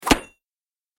دانلود آهنگ دعوا 35 از افکت صوتی انسان و موجودات زنده
دانلود صدای دعوا 35 از ساعد نیوز با لینک مستقیم و کیفیت بالا
جلوه های صوتی